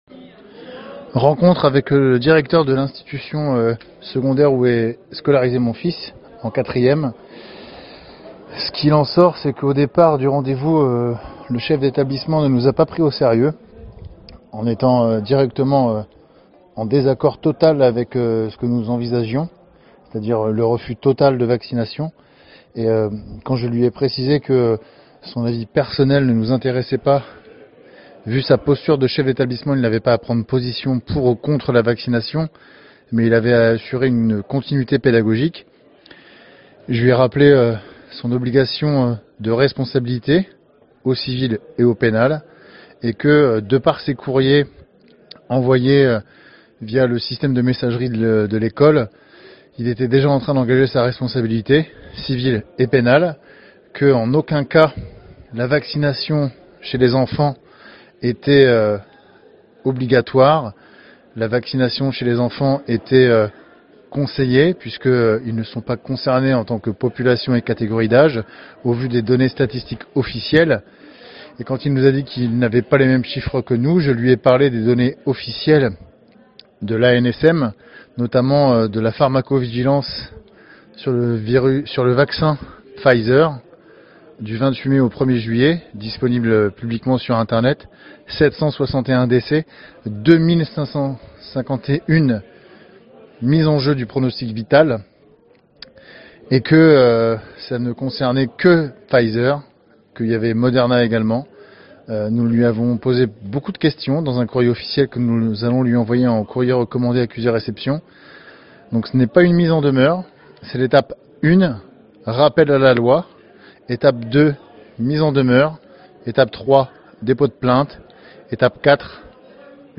Extrait audio récupéré sur Telegram.
C'est donc utile, pour ceux qui ont des enfants et risquent de faire face à ce genre de situation, de l'écouter et d'imiter sa démarche J'ai du le convertir du format OGG en MP3, la qualité n'est pas top mais suffisante.